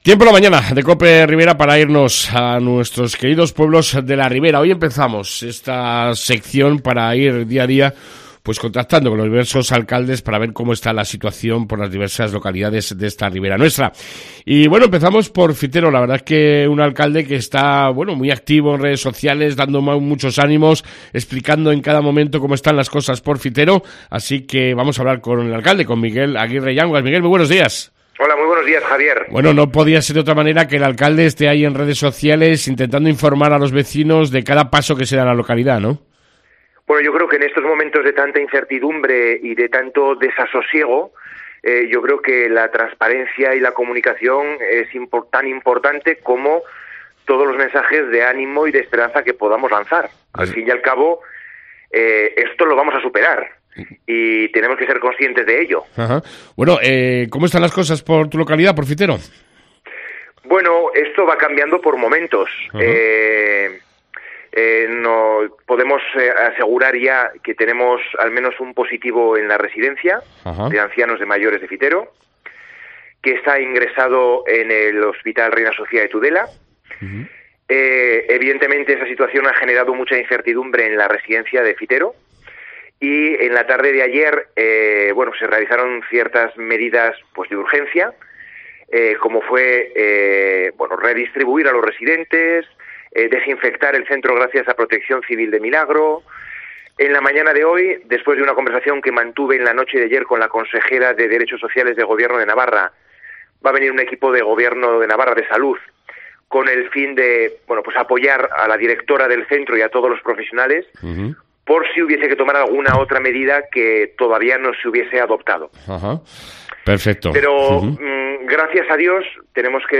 AUDIO: Entrevista con el Alcalde de Fitero Miguel Aguirre Yanguas